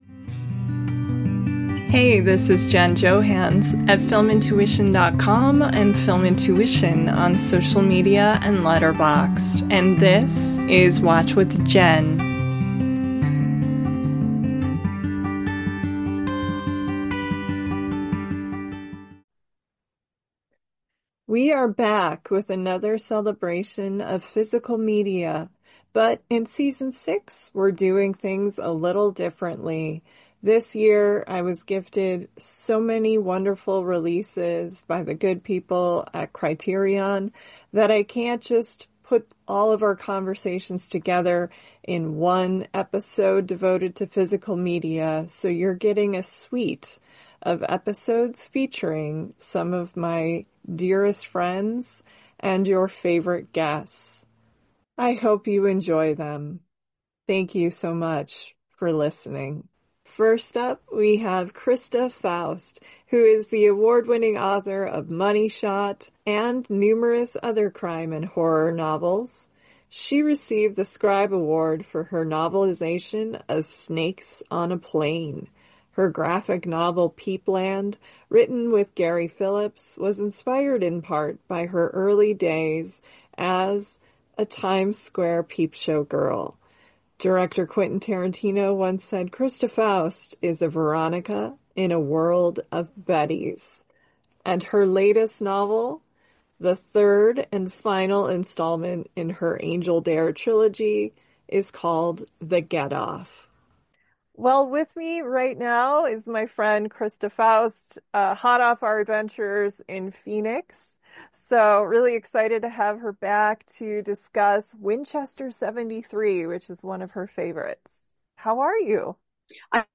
In the first part of a summer suite of Season 6 episodes devoted to Physical Media released this year by The Criterion Collection, you'll hear conversations with some of my dearest friends (& your favorite guests). Just in time for Father's Day, we're embracing Dudes Rock (or at times, ironically, "Dudes Don't Rock") Energy for the first quartet of classic film favorites.
Theme Music: Solo Acoustic Guitar